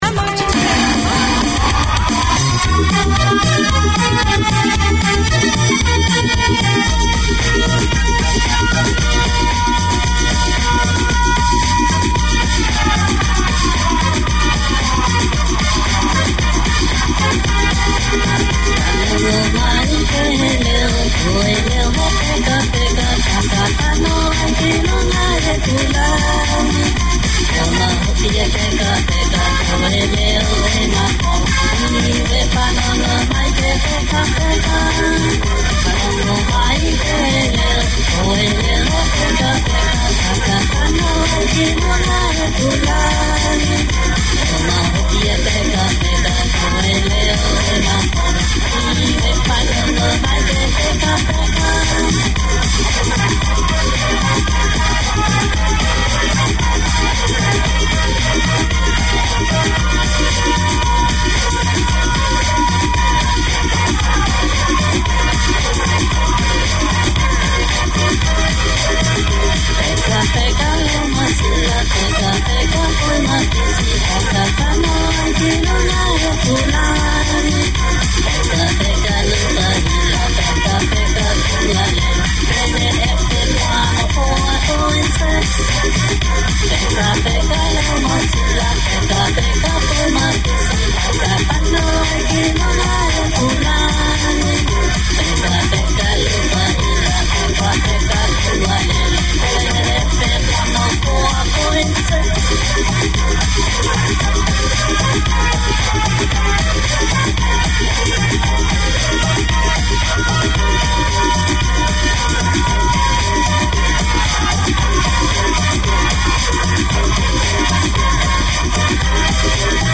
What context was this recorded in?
Music, global news and interviews with people from Niue and the Niuean community feature in this weekly hour.